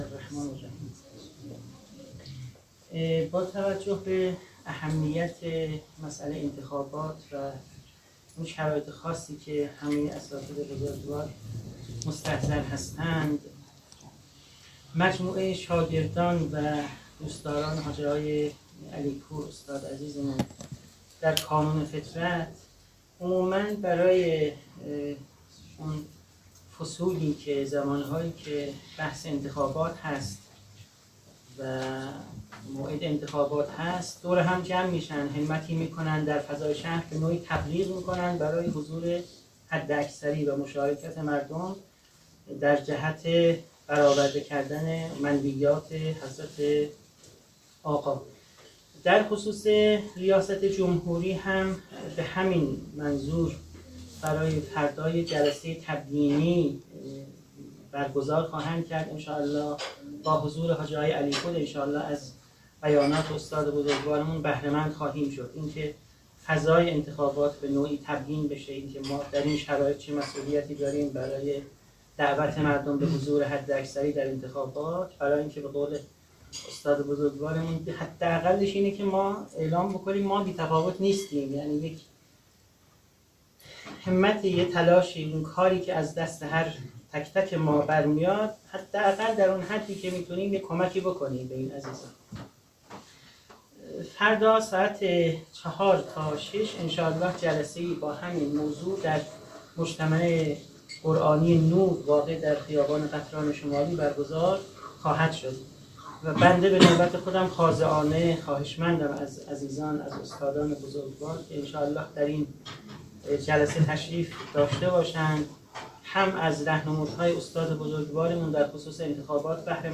ضربه های دشمن به جامعه اسلامی و وظایف ما در انتخابات - در جمع اساتید دانشگاه